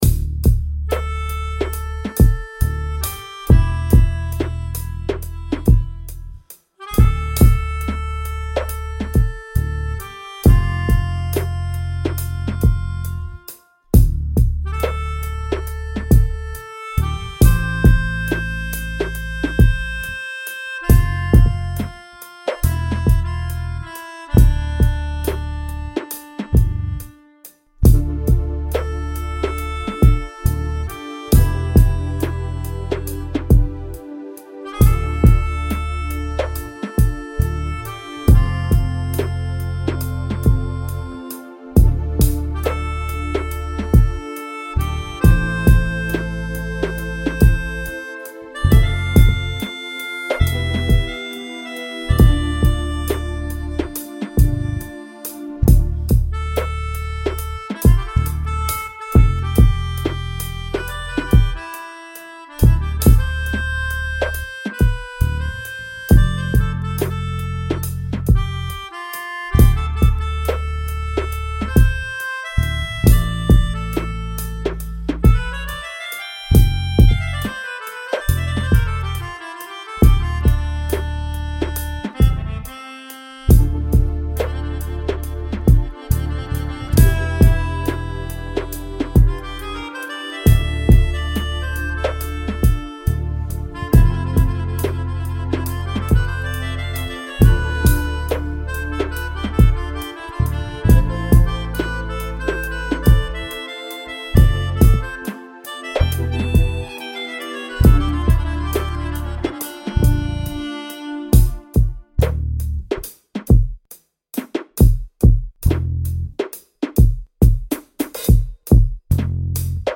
Today, I did an update on the mastering setup.